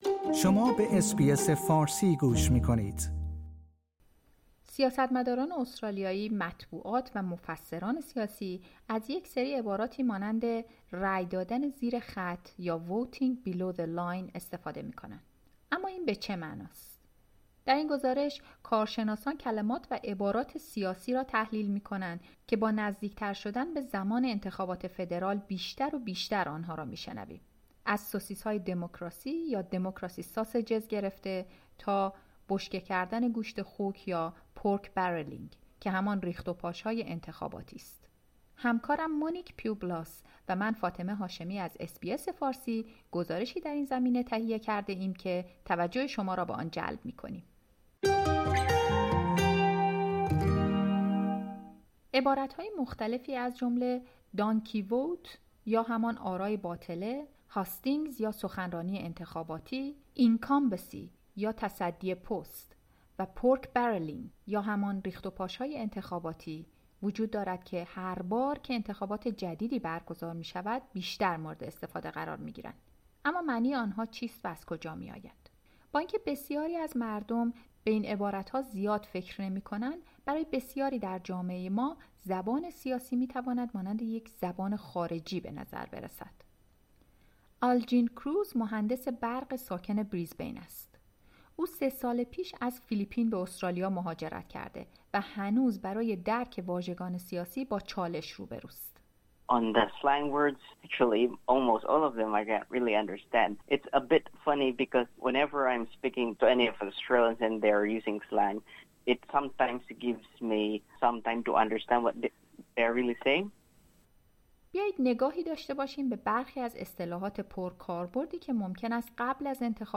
سیاستمداران استرالیایی، مطبوعات و مفسران سیاسی از یک سری عباراتی مانند 'voting below the line' استفاده می‌کنند، اما این به چه معناست؟ در این گزارش کارشناسان کلمات و عبارات سیاسی را تحلیل می‌کنند که با نزدیک‌تر شدن به انتخابات فدرال بیشتر آنها را می‌شنویم؛ از democracy sausages گرفته تا بشکه‌های گوشت خوک pork barrelling و بیشتر.